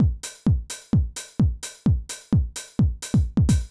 INT Beat - Mix 17.wav